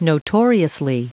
Prononciation du mot notoriously en anglais (fichier audio)